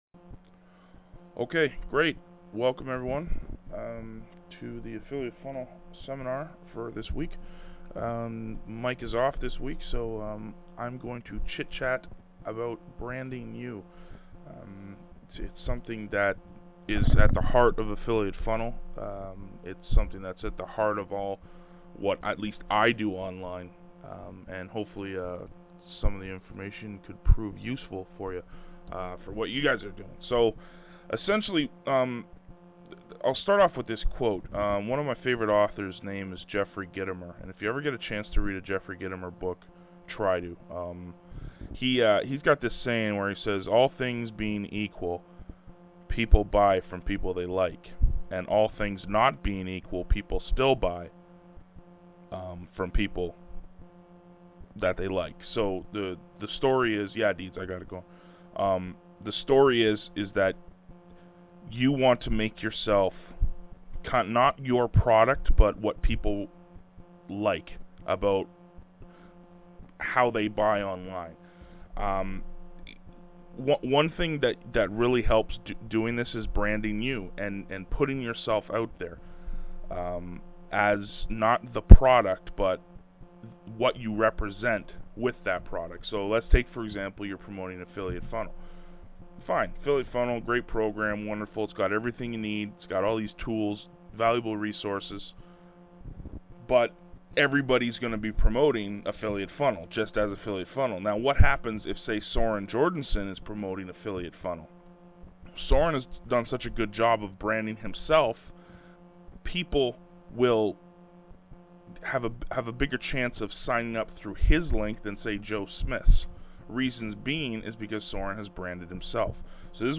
Branding You Seminar